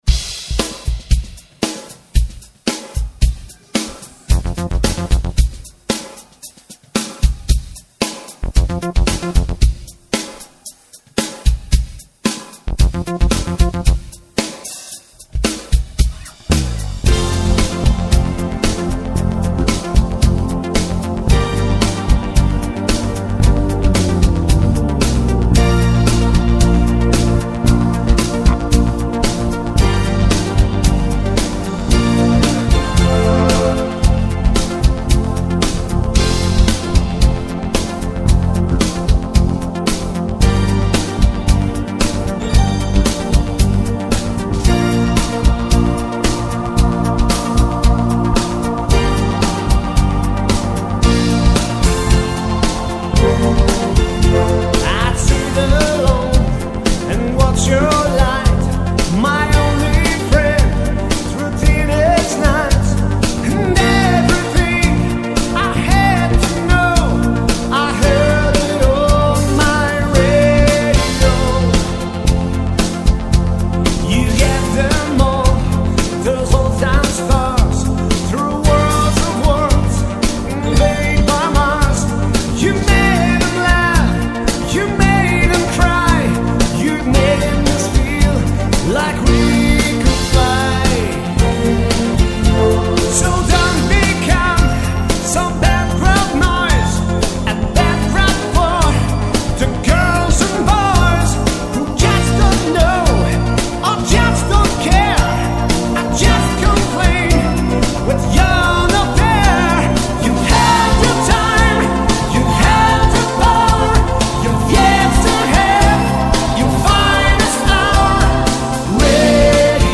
Die Aufnahmen stammen vom ersten Konzert Dezember 2005.